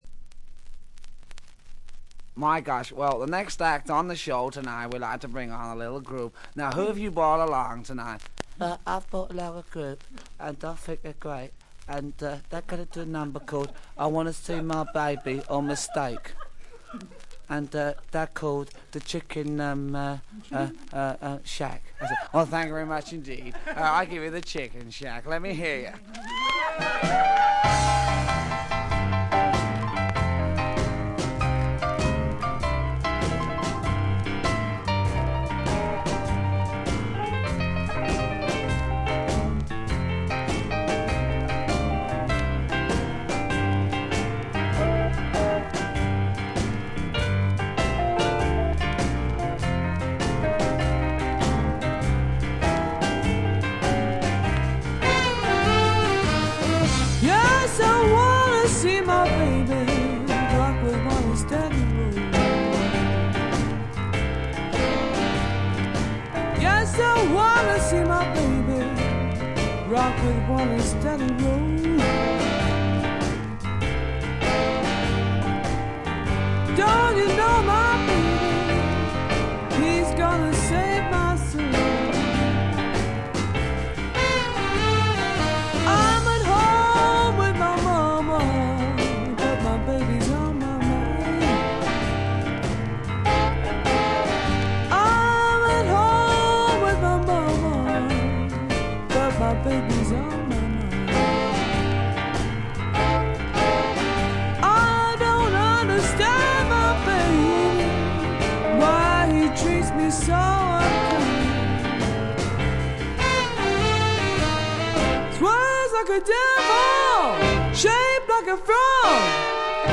チリプチや散発的なプツ音はそこそこ出ますが鑑賞を妨げるようなものではありません。
英国ブルースロック名作中の名作。
初期モノラル・プレス。
試聴曲は現品からの取り込み音源です。（ステレオ針での録音です）